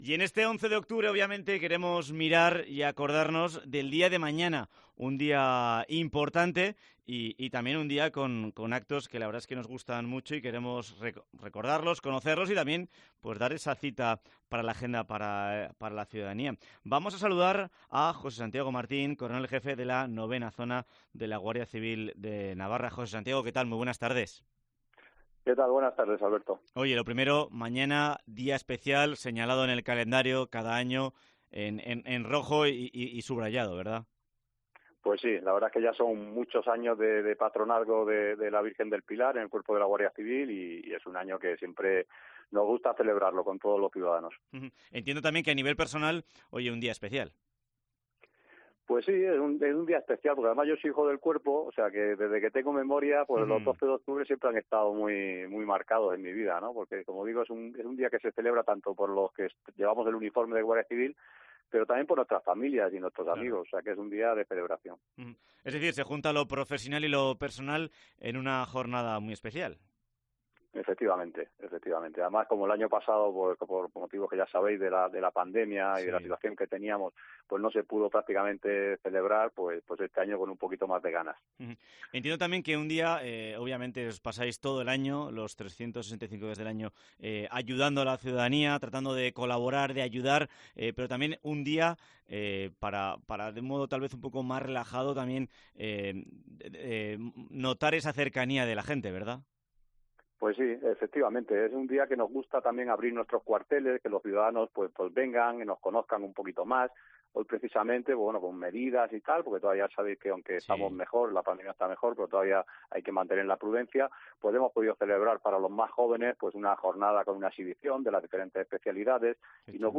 Entrevista con Santiago Martín, jefe de la 9ª Zona de la Guardia Civil de Navarra